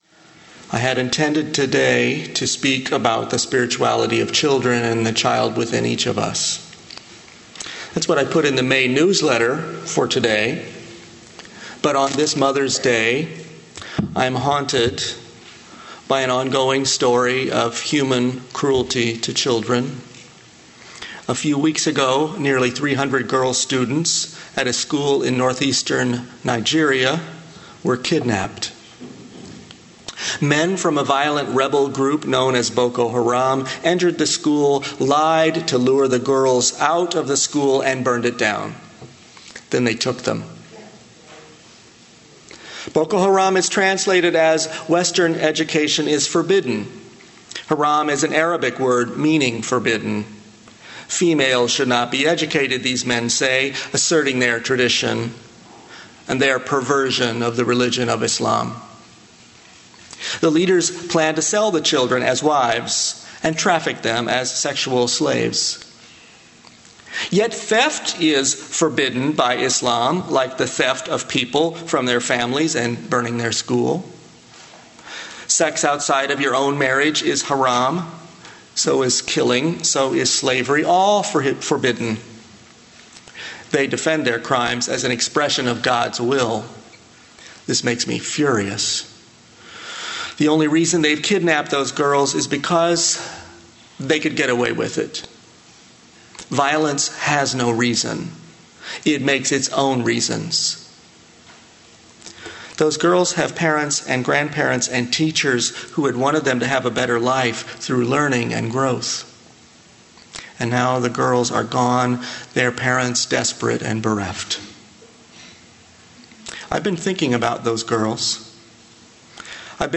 Sorry, no description of this sermon available.